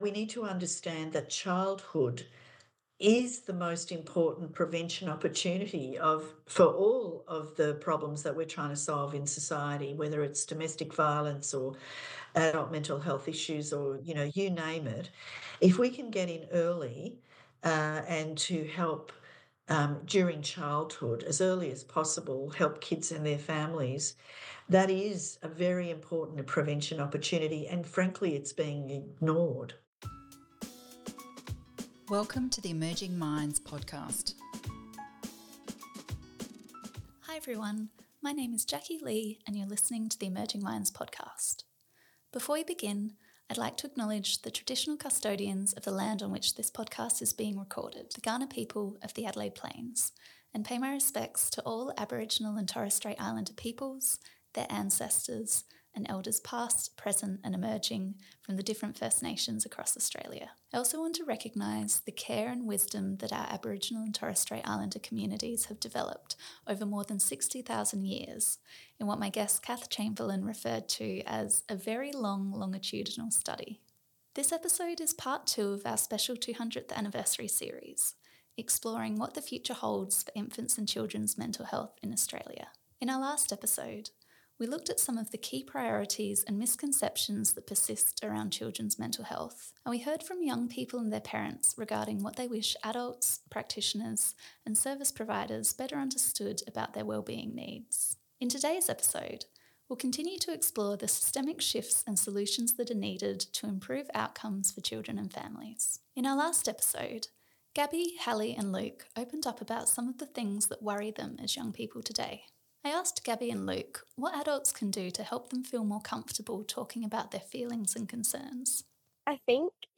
In this episode, practitioners, researchers, parents and young people explore strategies and shifts to better support infant, child and adolescent mental health in Australia. They discuss the need for better collaboration between professionals and more integrated services; for improving mental health literacy among community members; and for shifting away from using diagnostic thresholds to determine who can access mental health support.